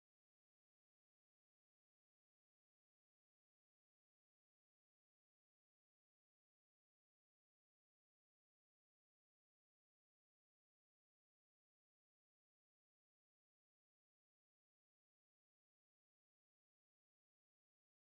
Hilfe bei Aufnahme von Metal-Gitarre
Zunächst einmal mit dem Le456 und einmal wie beim angehefteten Screenshot.